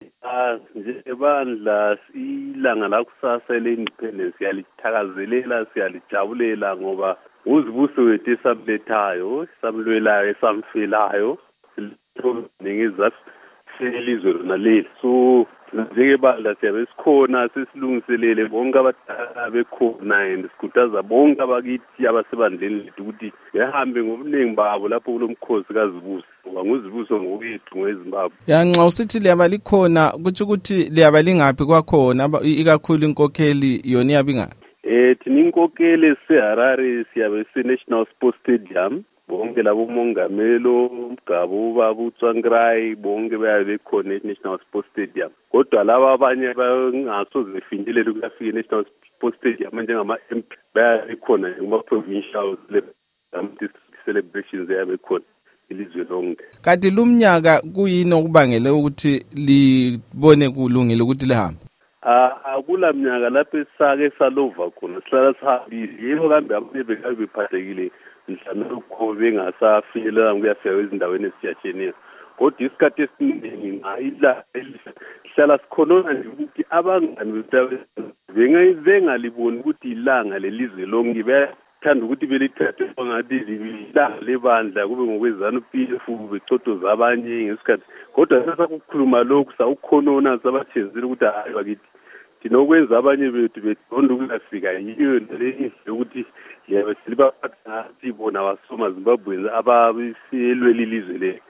Embed share Ingxoxo loMnu.